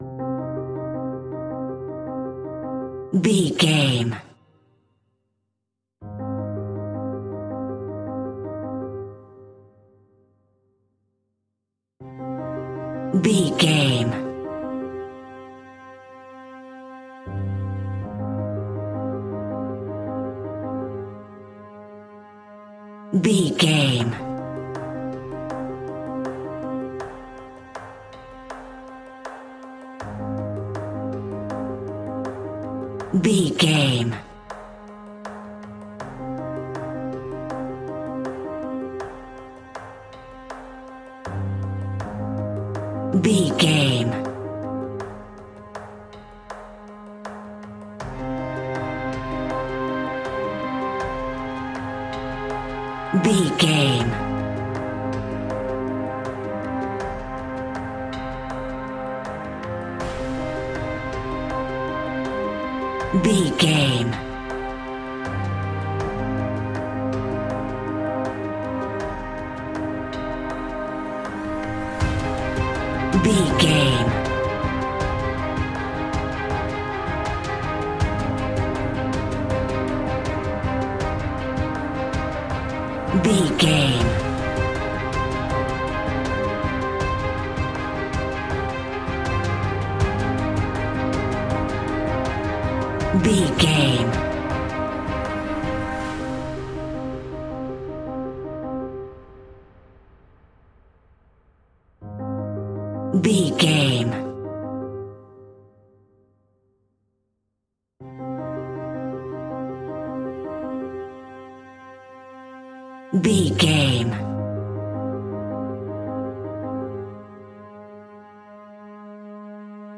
Aeolian/Minor
scary
ominous
dark
suspense
haunting
eerie
horror piano